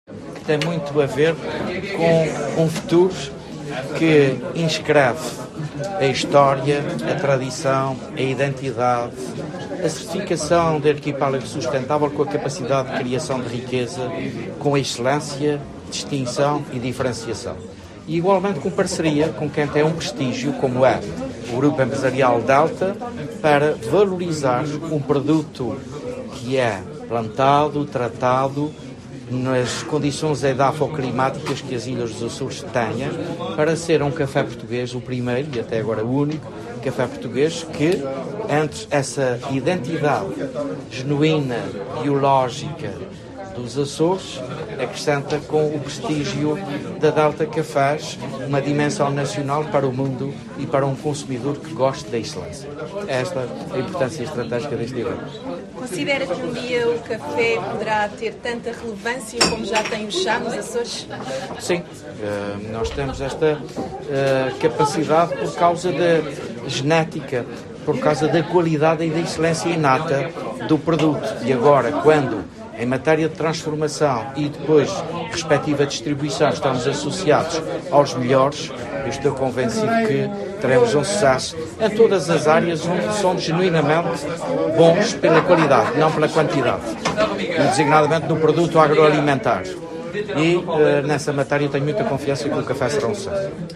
Falando em Lisboa, no lançamento do produto, o governante mostrou-se orgulho com a “excelência inata” do café.